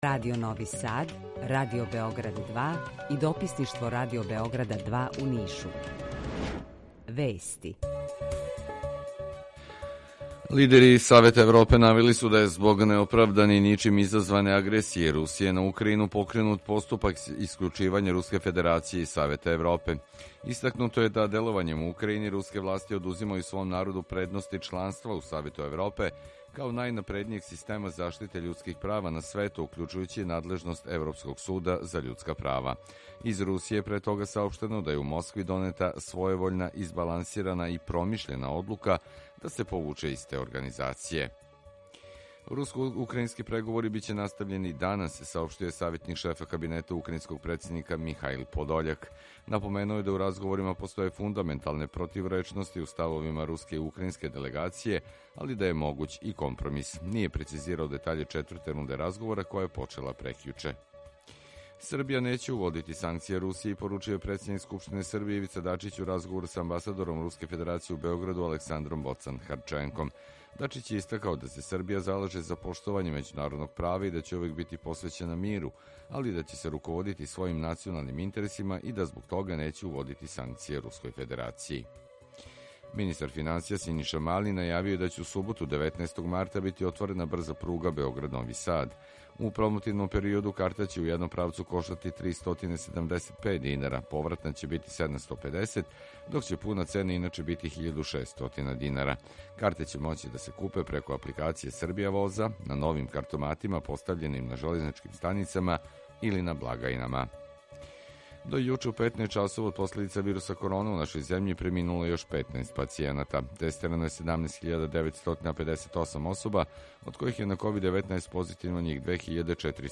Укључење из Косовске Митровице
Јутарњи програм из три студија
У два сата, ту је и добра музика, другачија у односу на остале радио-станице.